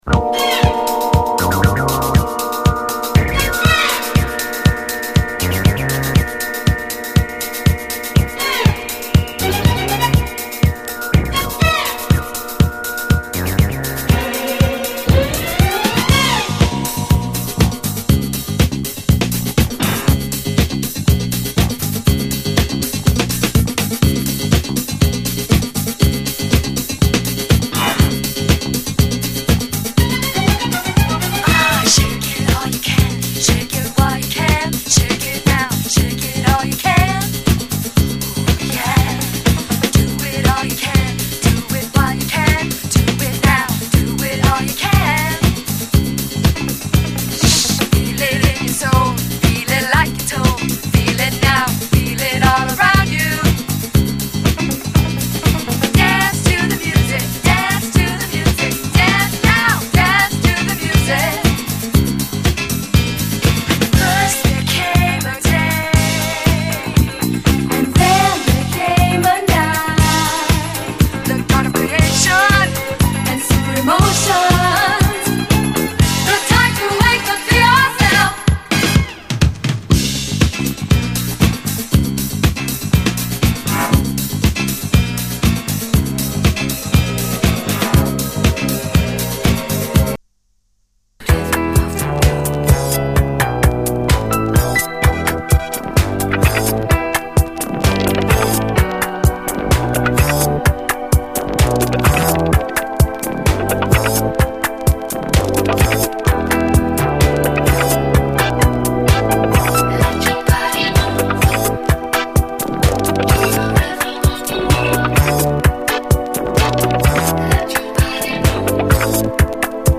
モッド・ファンク〜ノーザン